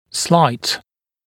[slaɪt][слайт]незначительный, слабый, легкий